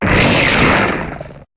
Cri de Golgopathe dans Pokémon HOME.